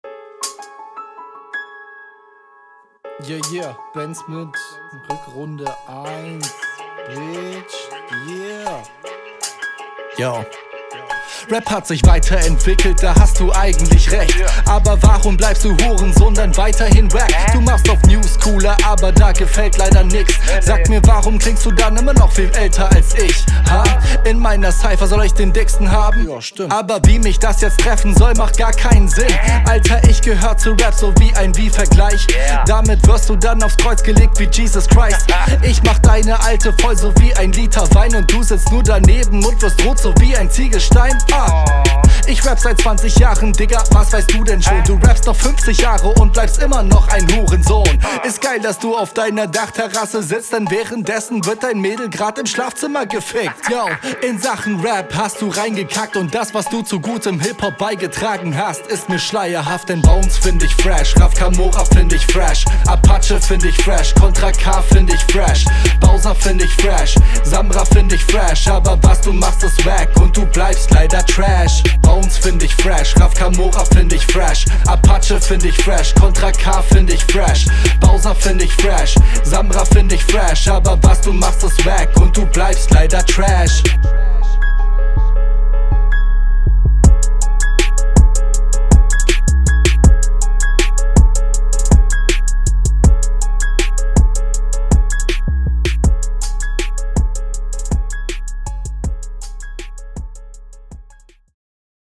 Flowlich steigst du druckvoller ein als der Gegner und die Variationen gefallen mir, weshalb ich …
Teils super ausgelutschte Reime, Lines auch eher ehhh aber besser gerappt